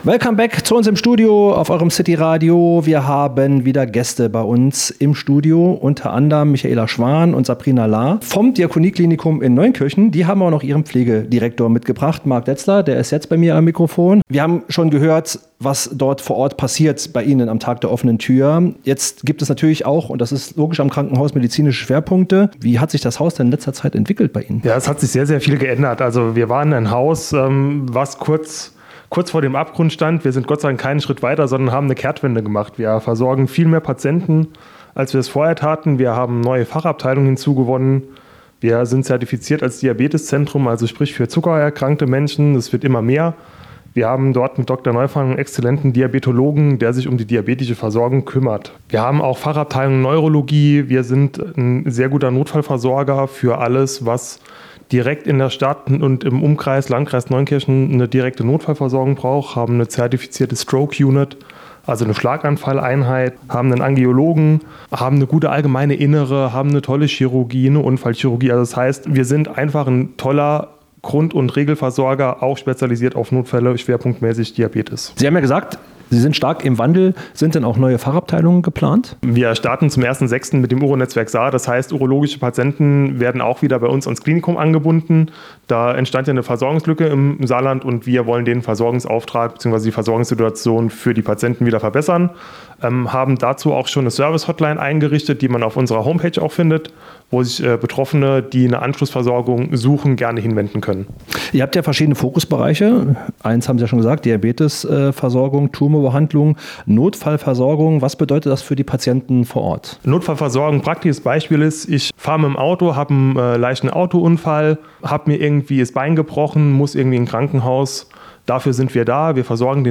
Im Studio von CityRadio Saarland durften wir kürzlich drei spannende Gäste begrüßen: